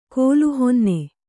♪ kōlu honne